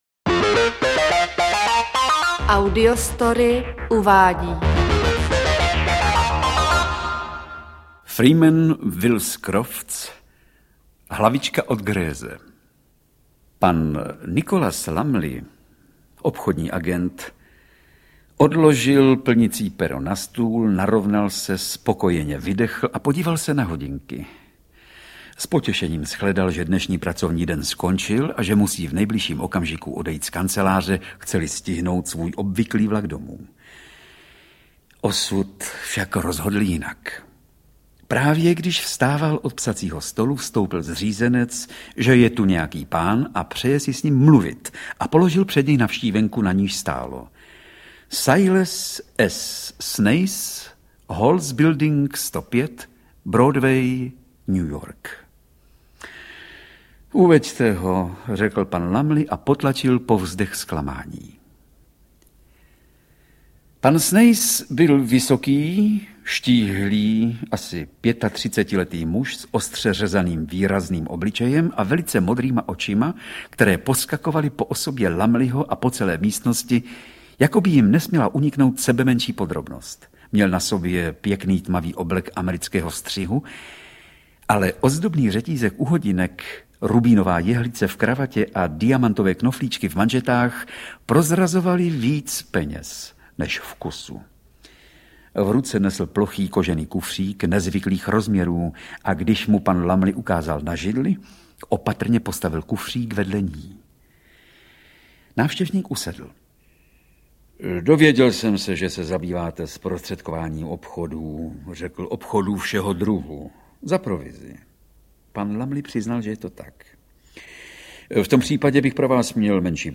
Interpret:  Vladimír Brabec
Čte Vladimír Brabec.
AudioKniha ke stažení, 2 x mp3, délka 1 hod. 12 min., velikost 65,9 MB, česky